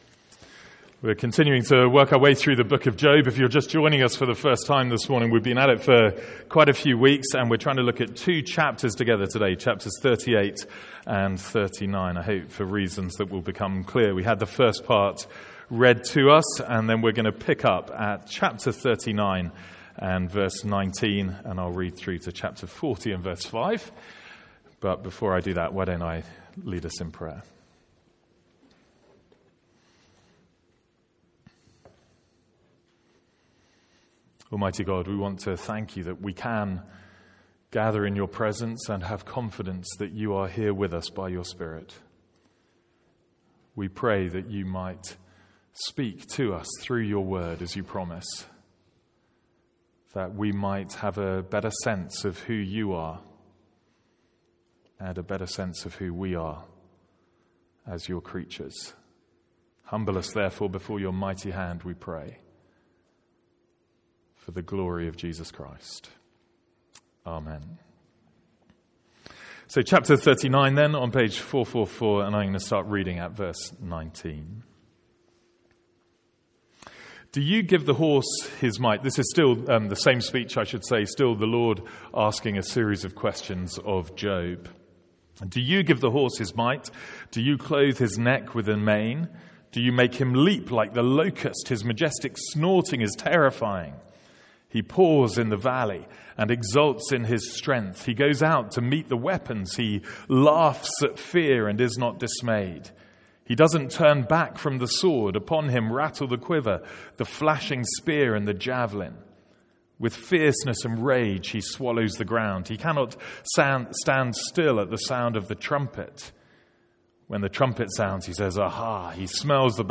From our morning service in Job.